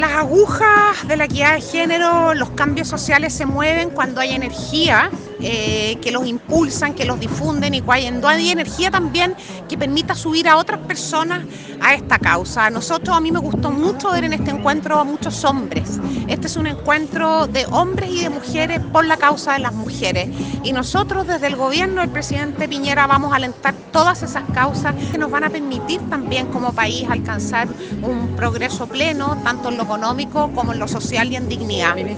Ministra de la Mujer y Equidad de Género, Isabel Pla.